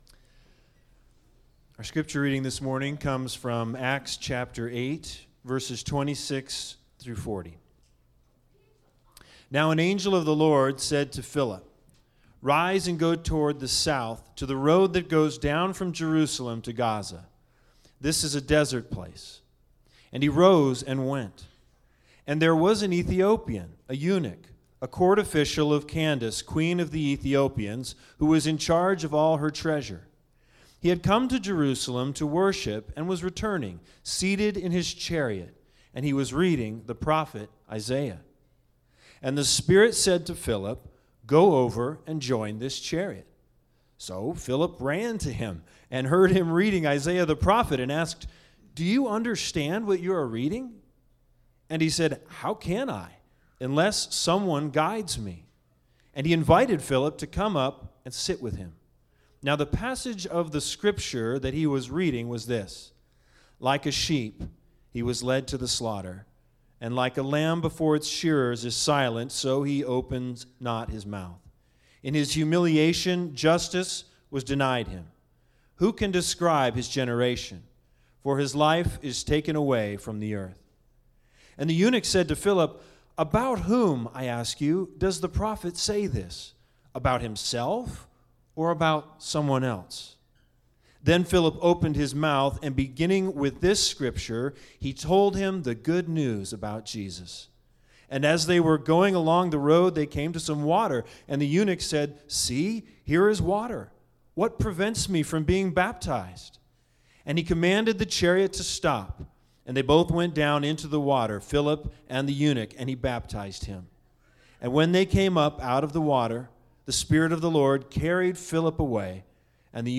Acts 8:26-40 Service Type: Special Sermons The Big Idea